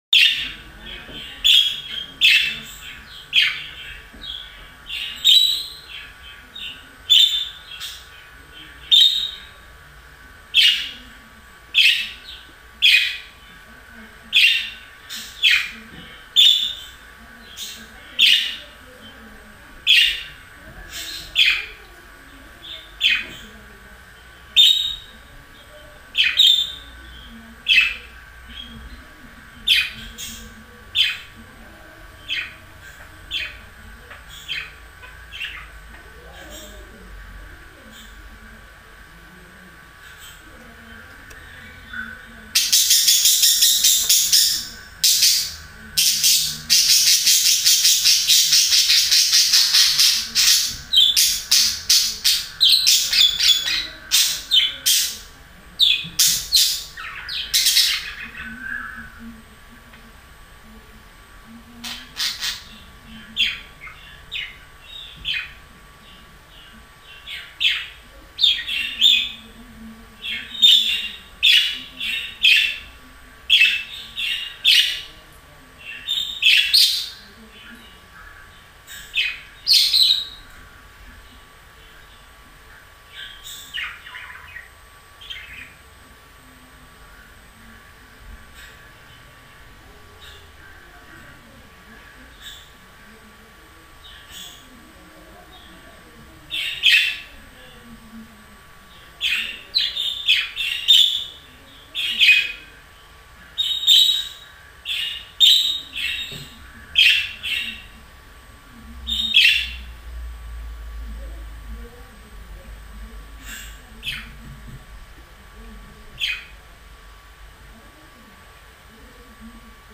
دانلود صدای مرغ عشق ماده از ساعد نیوز با لینک مستقیم و کیفیت بالا
جلوه های صوتی